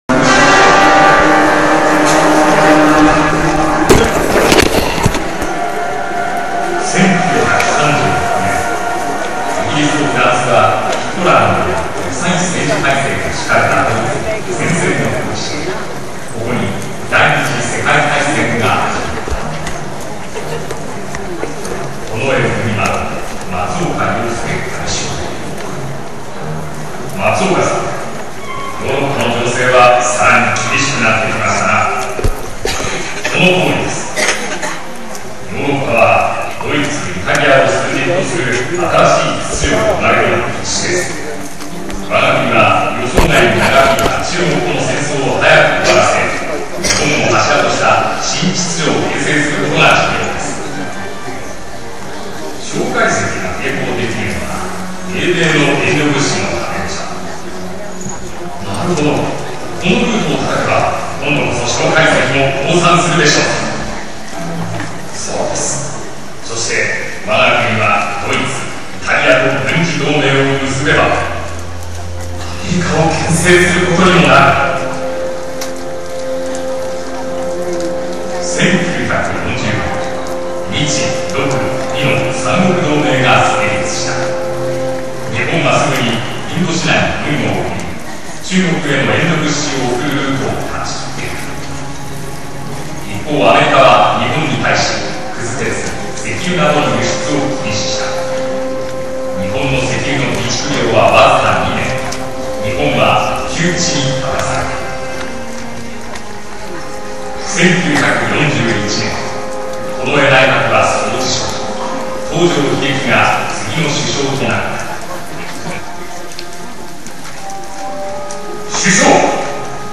ジ ョ イ ン ト リ サ イ タ ル
平成十九年八月二十六日 (日)　於：尼崎アルカイックホール